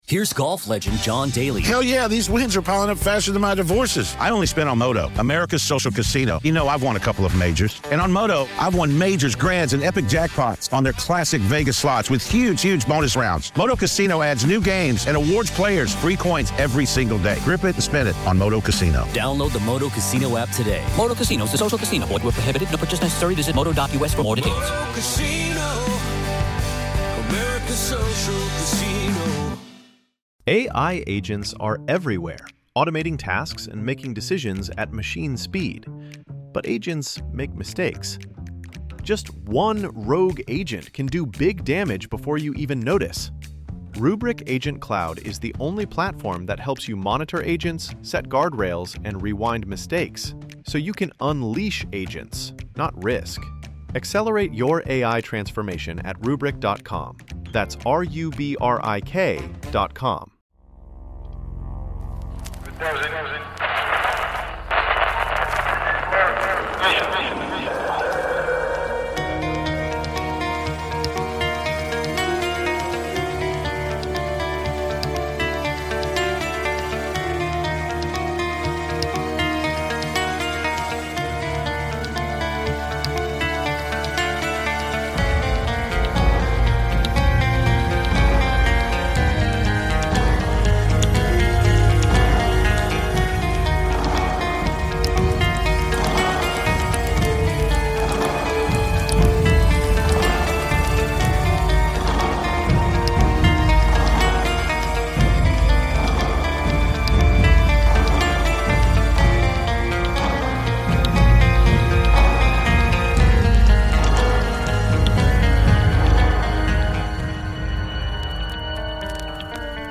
The season finale Hometown Legends Special! Listeners share spooky local lore and legends from their hometowns.